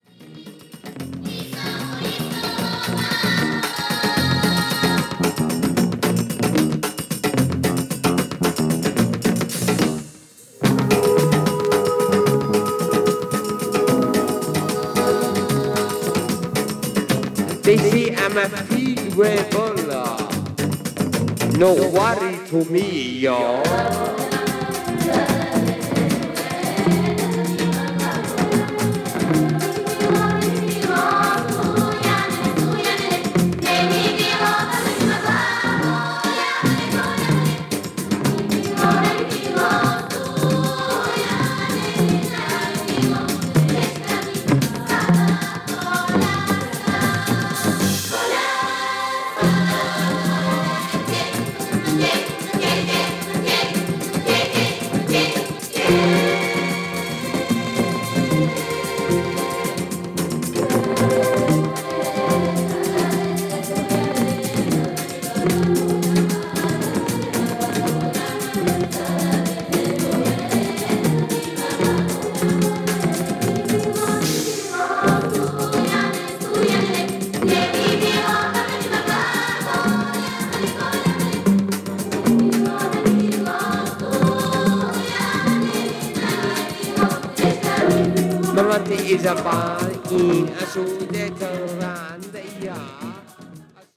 Bass
Percussion
Guitar